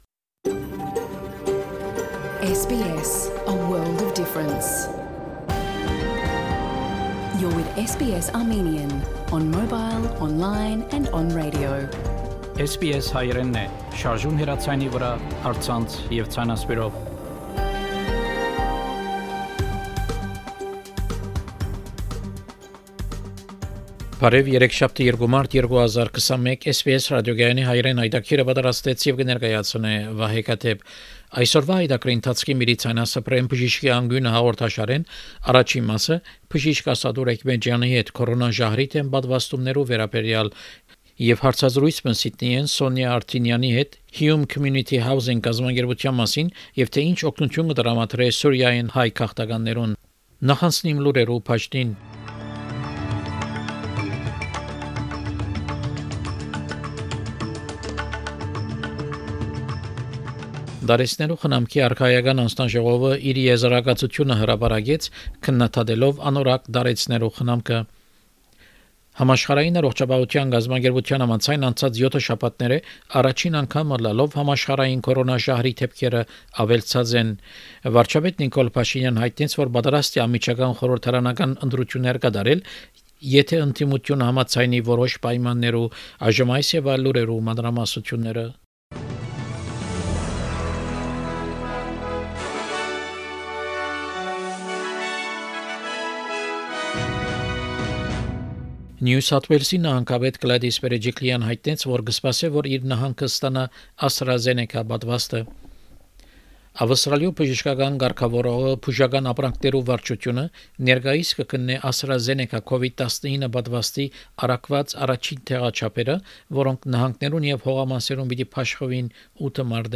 SBS Armenian news bulletin – 2 March 2021